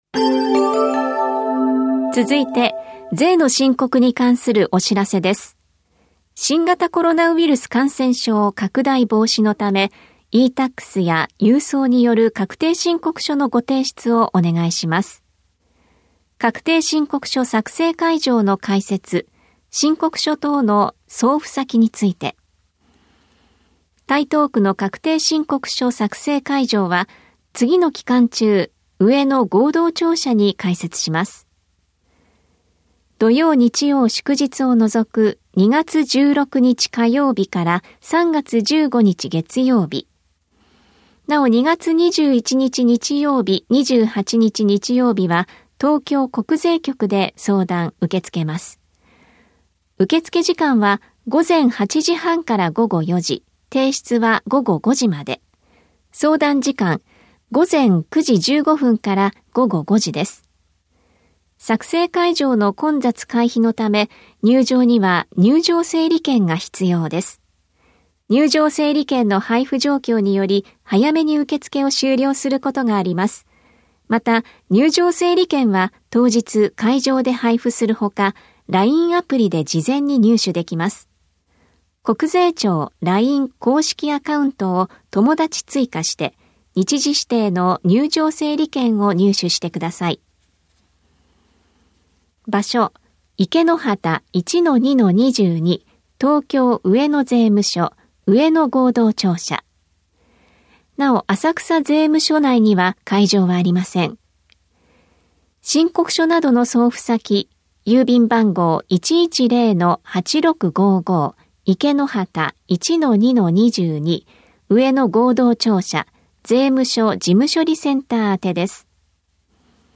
広報「たいとう」令和3年1月20日号の音声読み上げデータです。